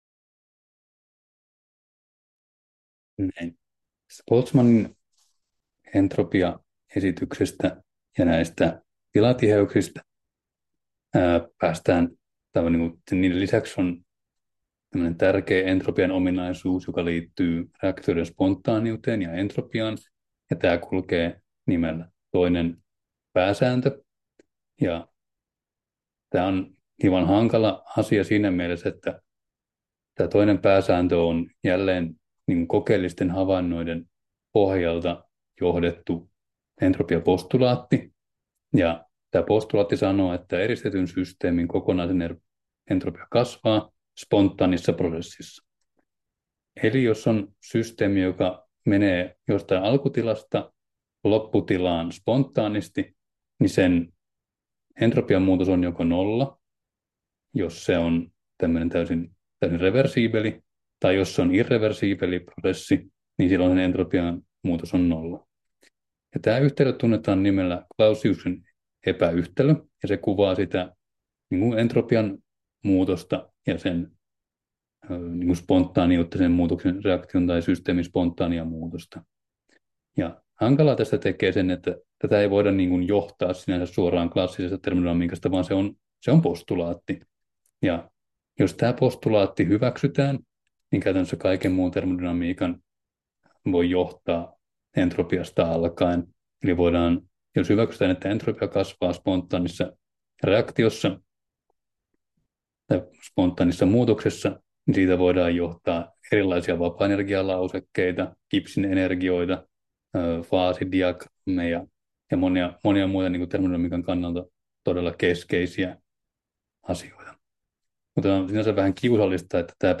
Luento 5: Entropia 6 — Moniviestin